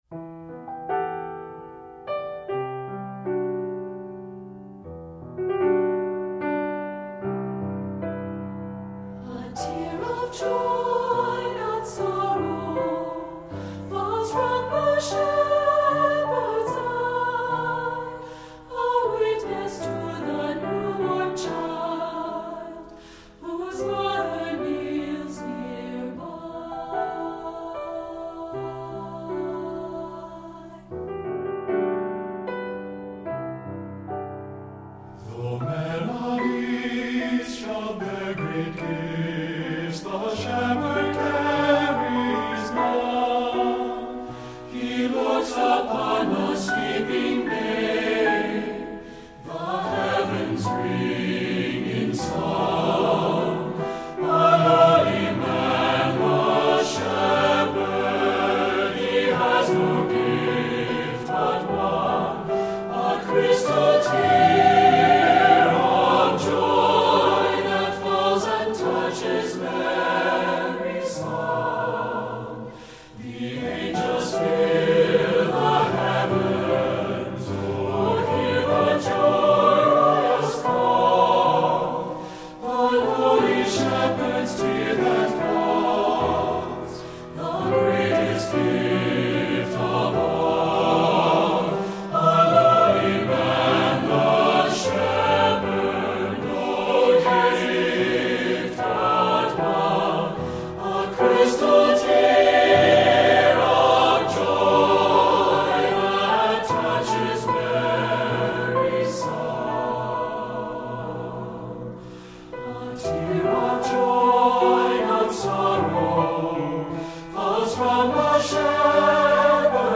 SAB Church Choir Music
Voicing: SATB